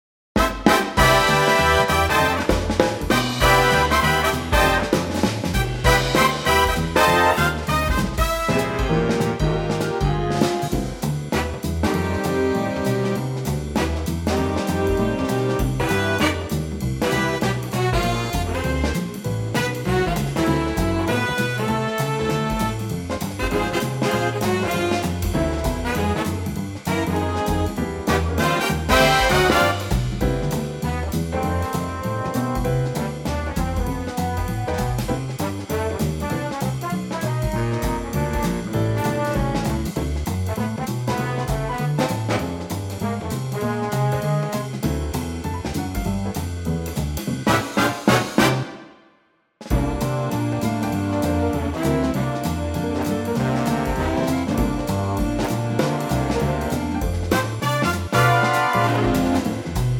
keys C to Db
8 bar intro and vocal in at 11 seconds
keys - C to Db - vocal range - C to F
Wonderful big band cover arrangement